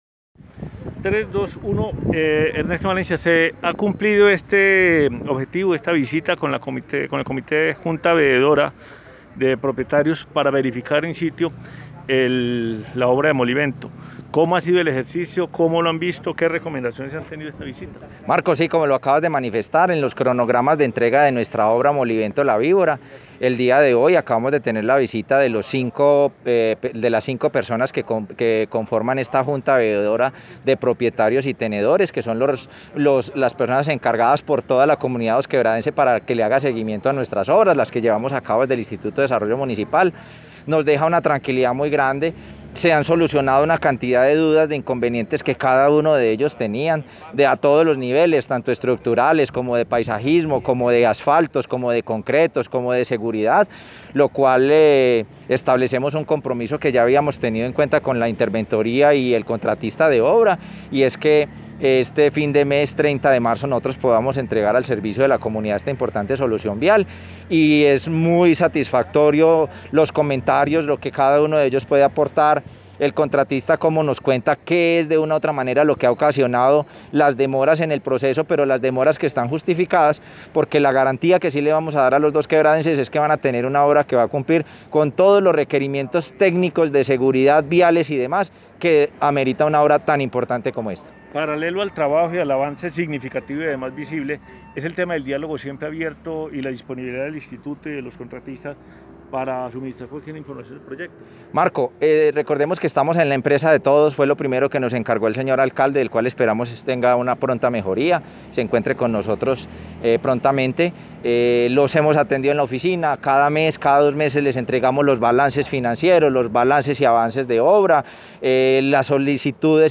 Escuchar Audio: Director del Instituto de Desarrollo Municipal – IDM, Luis Ernesto Valencia Ramírez.
Comunicado-090-Director-IDM-Ernesto-Valencia.mp3